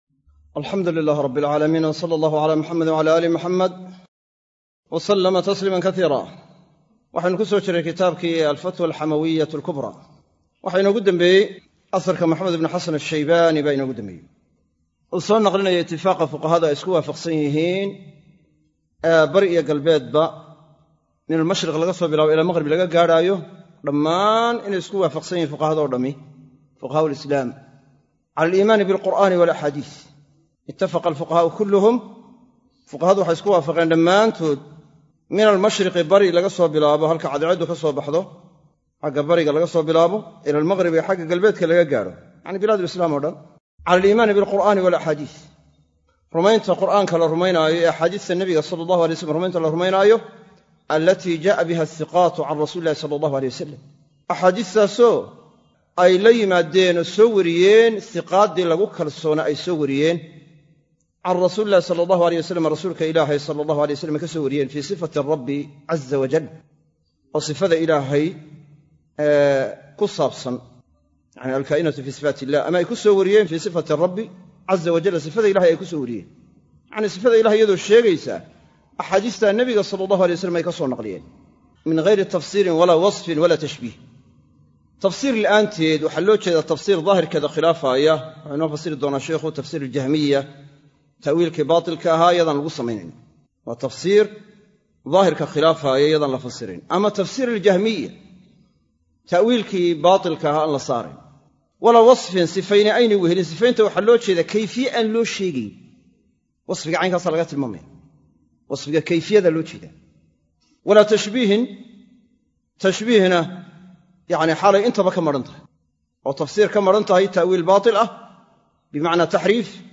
Sharaxa Kitaabka Al-Fatwa Al-Xamawiyyah Al-Kubraa - Darsiga 20aad - Manhaj Online |